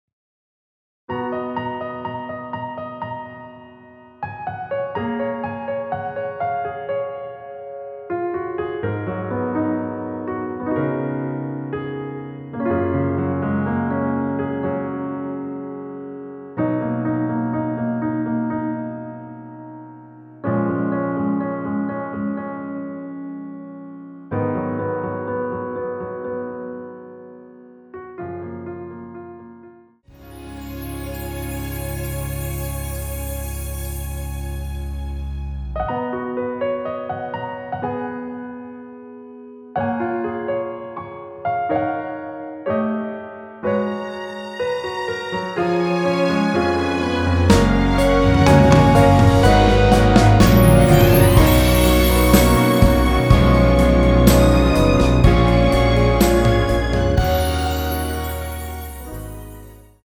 원키에서(-2)내린 MR입니다.
F#
앞부분30초, 뒷부분30초씩 편집해서 올려 드리고 있습니다.
중간에 음이 끈어지고 다시 나오는 이유는